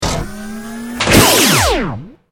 battlesuit_medlaser.ogg